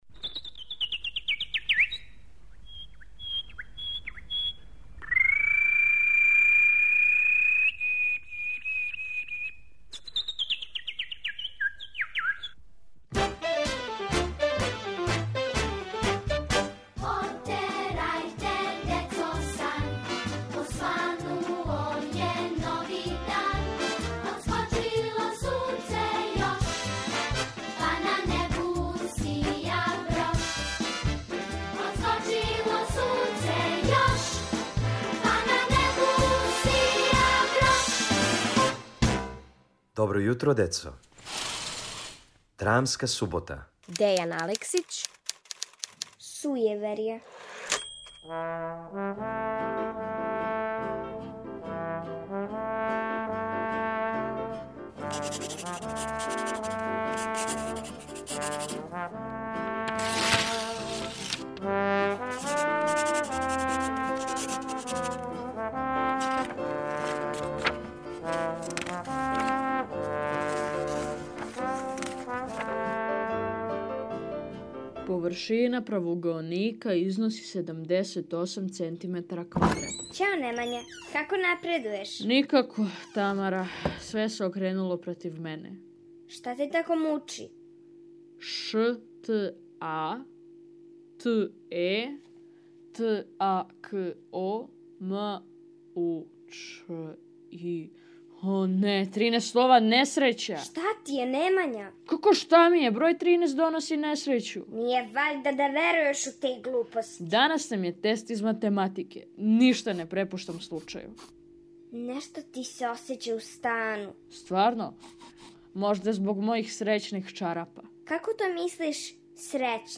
Да ли ће Немања имати среће на тесту из математике, сазнајте у краткој драми "Сујеверје" Дејана Алексића.